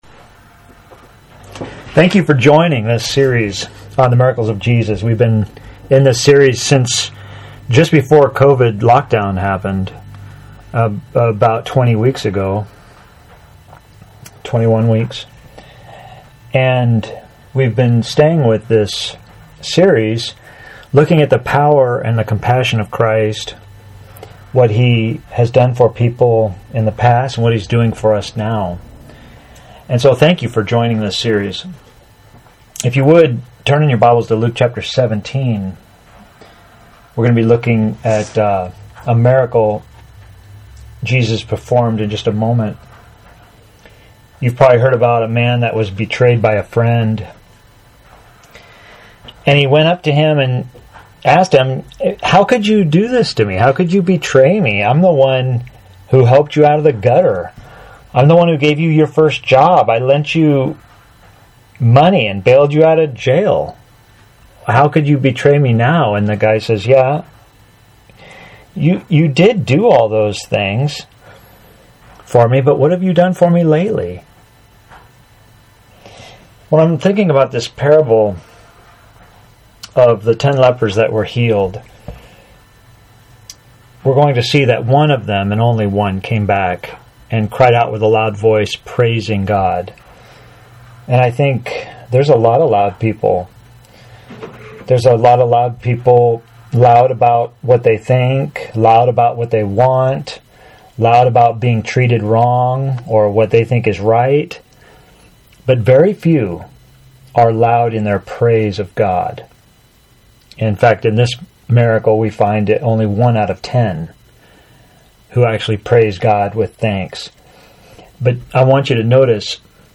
A.W. Tozer Sermon for August 16, 2020 – AUDIO | TEXT PDF Where are the Nine?